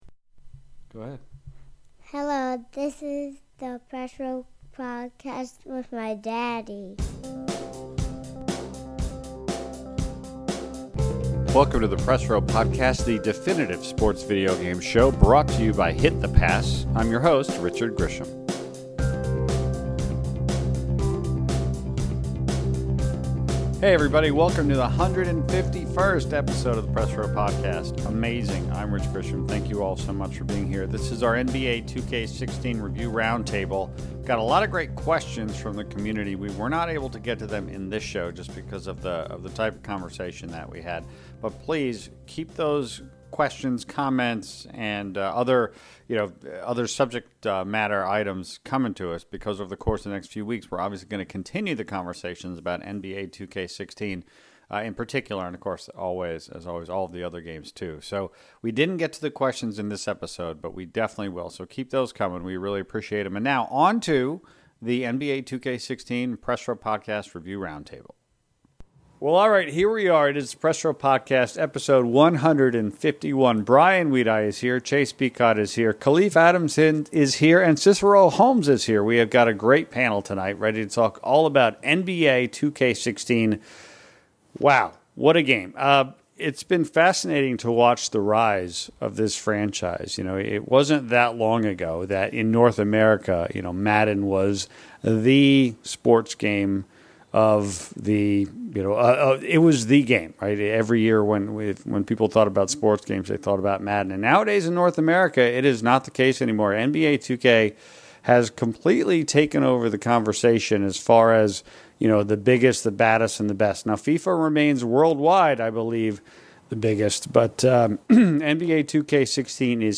NBA 2K16 Review Roundtable
The 151st episode of the Press Row Podcast gets all our hoops-loving friends together to chat all about NBA 2K16. The panel discusses the on-court gameplay, the (yet again) modified controls, the online play, the controversial Spike Lee story mode, and some of the interesting moments we’ve experienced in the launch window of one of the most ambitious sports games of all time.